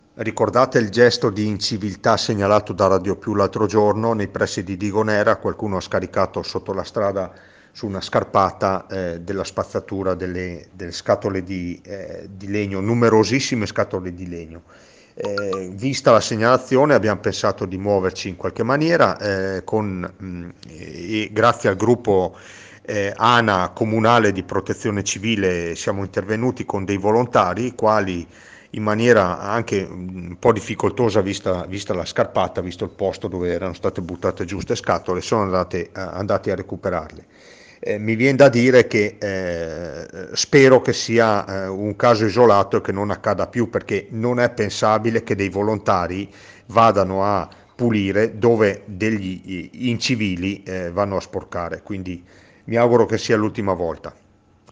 IL SINDACO DI ROCCA PIETORE ANDREA DE BERNARDIN